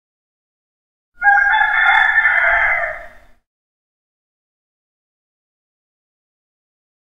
دانلود صدای خروس 3 از ساعد نیوز با لینک مستقیم و کیفیت بالا
جلوه های صوتی